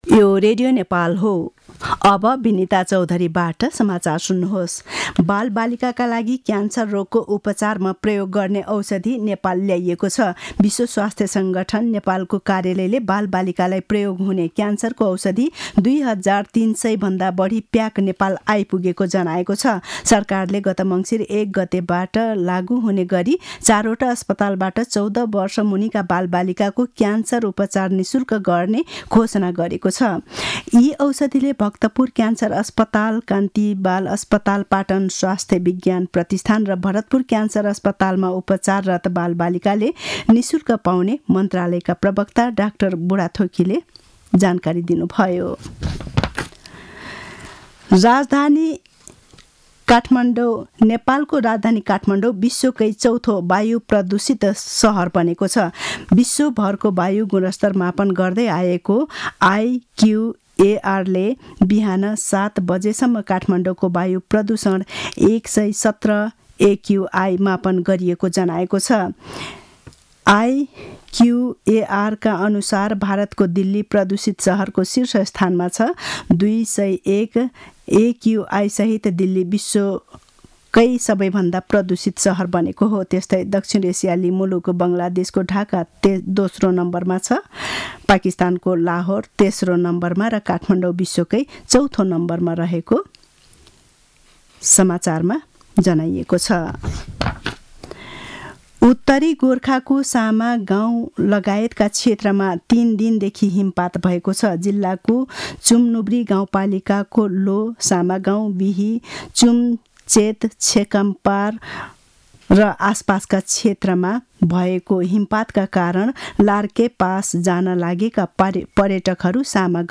दिउँसो १ बजेको नेपाली समाचार : ६ फागुन , २०८१